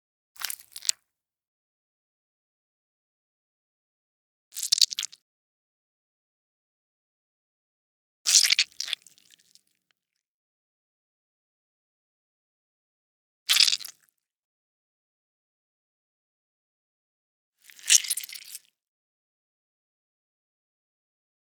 horror
Flesh Small Squish 2